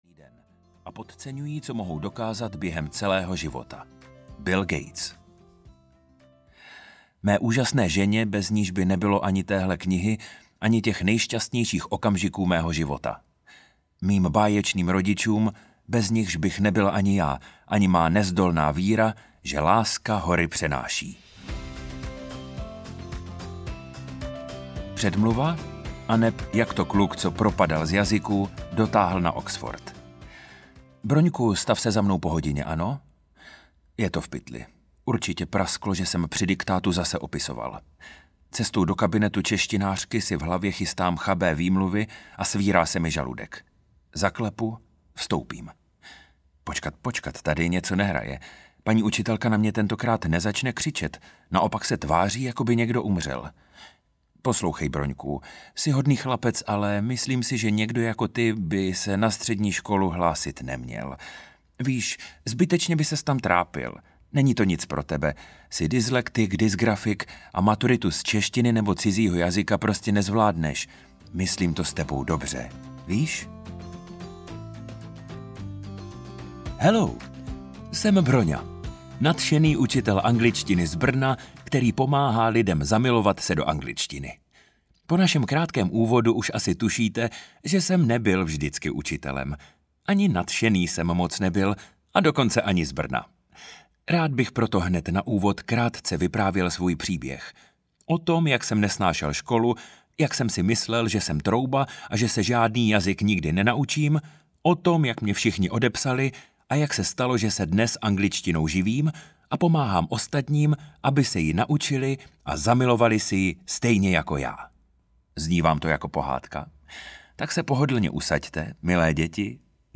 Zamilujte se do angličtiny audiokniha
Ukázka z knihy